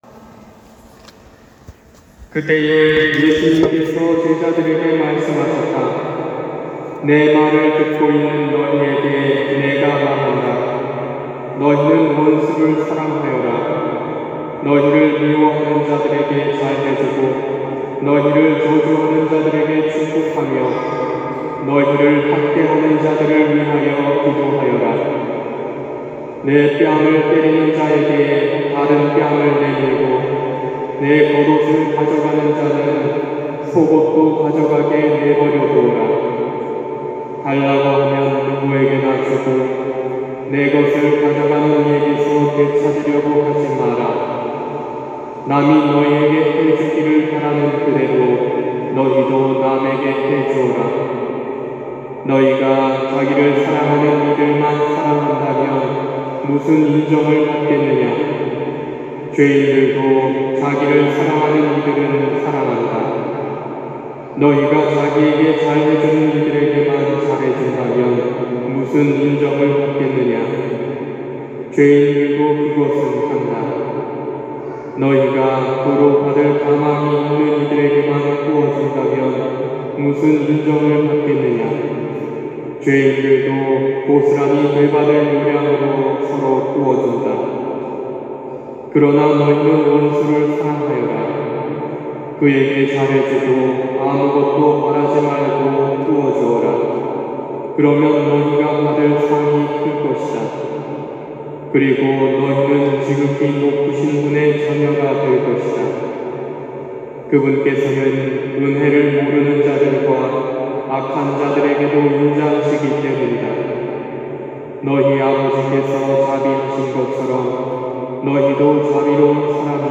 250222 신부님 강론 말씀